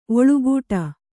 ♪ oḷugūṭa